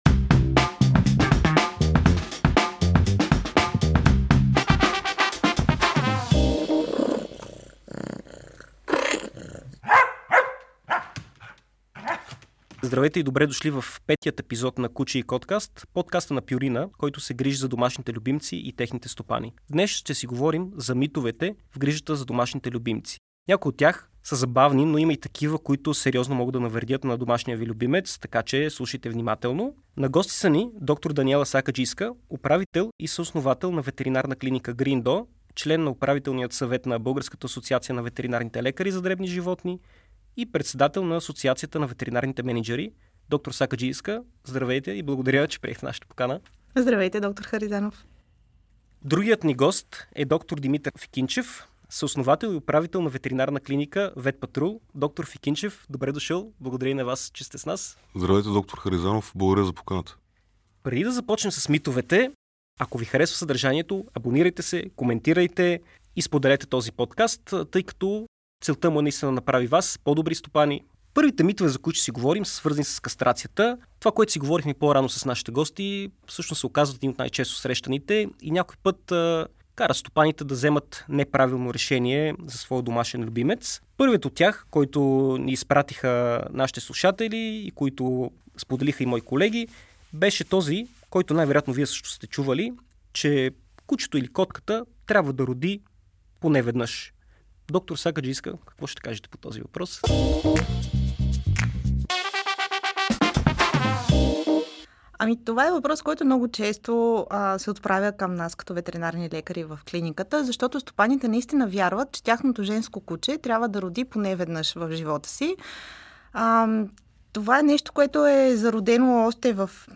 Трябва ли кучето да се храни със сурова храна, полезно ли е за него да яде кокали и кога е добре да бъде направена кастрацията? Всичко това ще научите от разговора на тримата специалисти в този епизод.